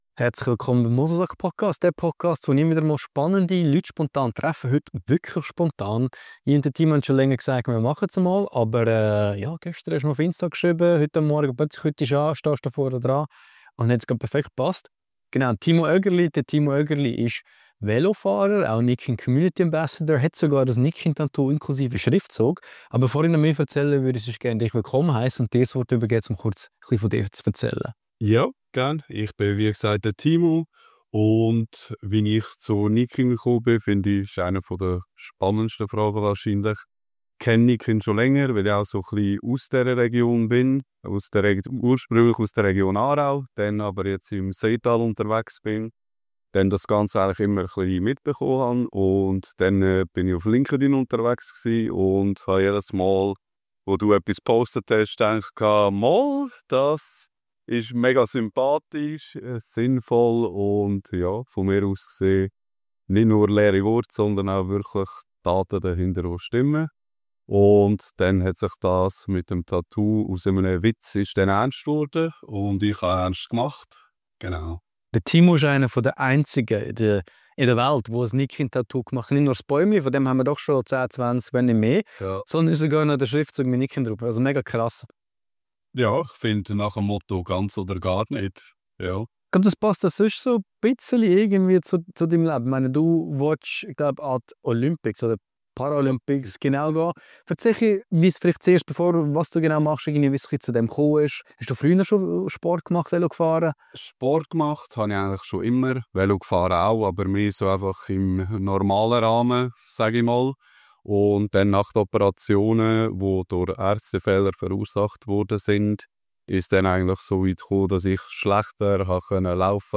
Ein Gespräch über Mut, Identität, Nachhaltigkeit und den unerschütterlichen Willen, seine Träume zu verwirklichen.